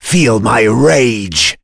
voices / heroes / en
Dimael-Vox_Skill1_c.wav